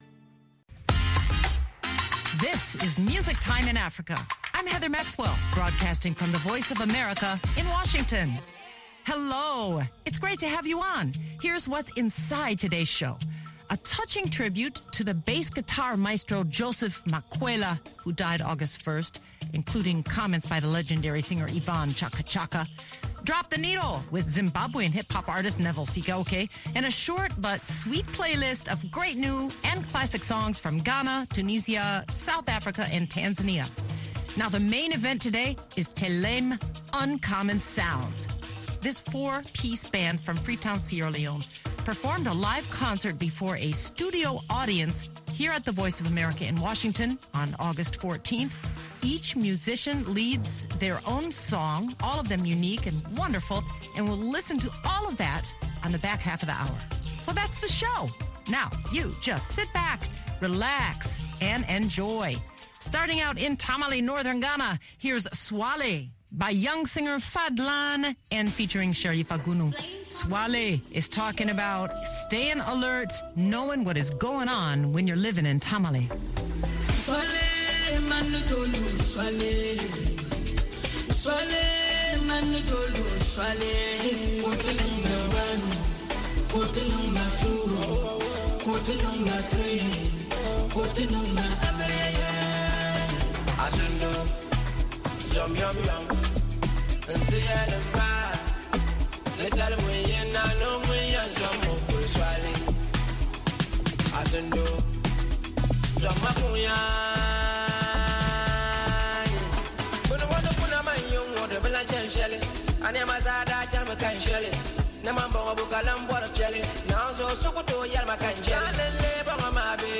At the back half of the hour listen to Telem Uncommon Sounds of Freetown, Sierra Leone perform before a live studio audience at VOA in Washington DC.